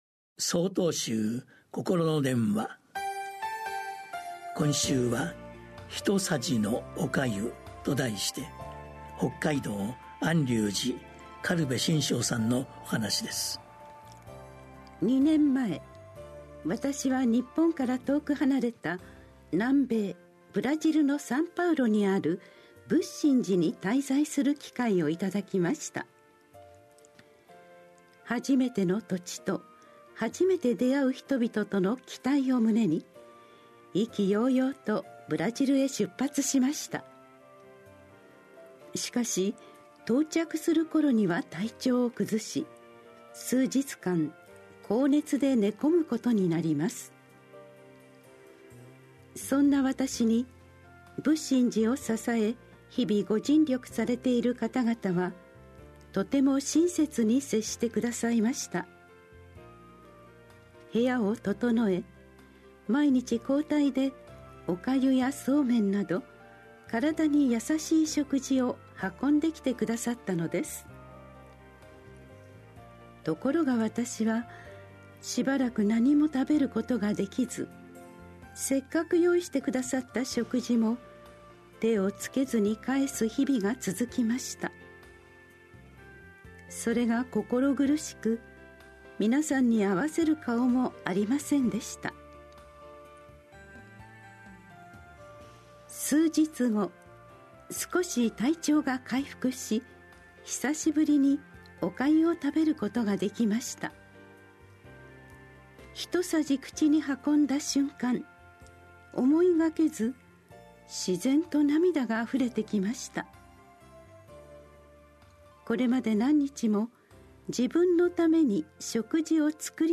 曹洞宗では毎週、わかりやすい仏教のお話（法話）を、電話と音声やポッドキャストにて配信しています。